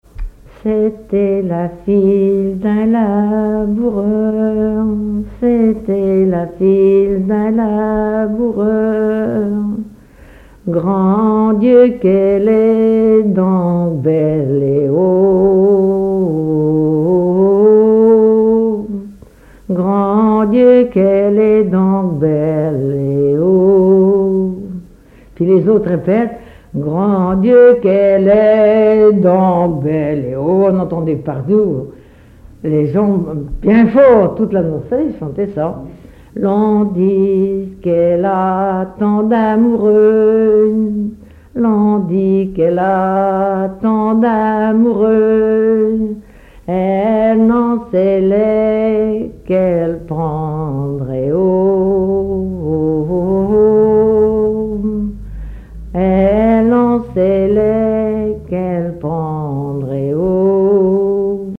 collecte en Vendée
Témoignages et chansons traditionnelles
Pièce musicale inédite